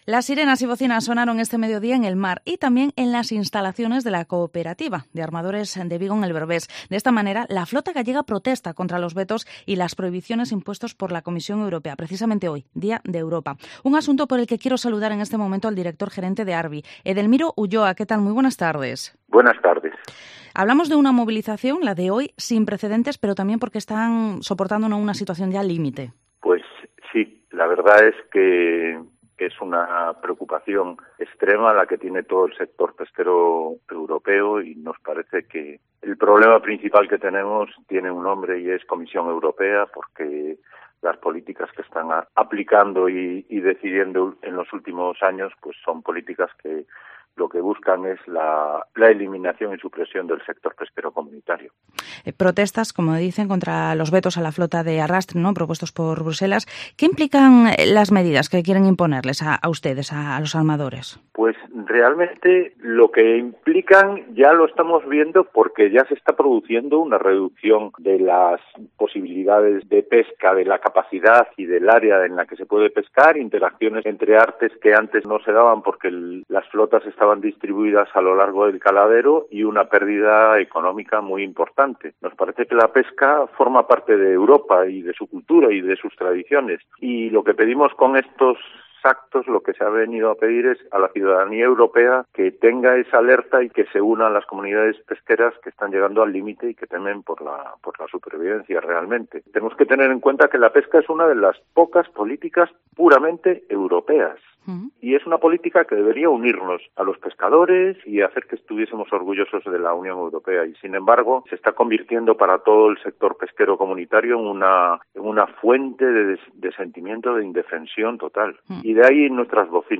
Vigo Entrevista La flota gallega protesta contra las medidas de Bruselas.